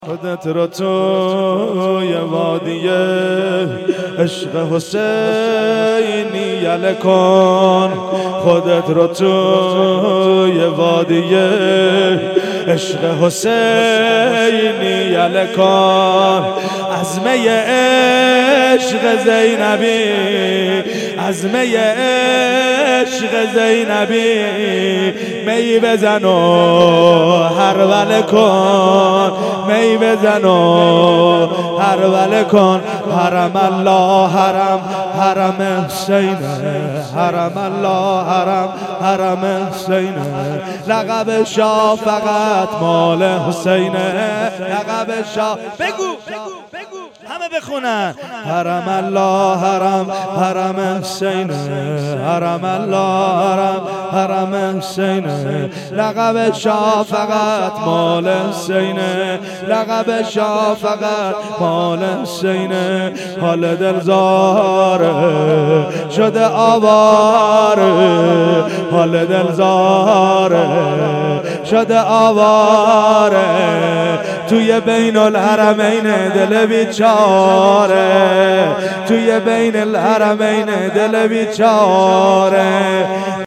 شور
شهادت امام صادق علیه السلام-شب دوم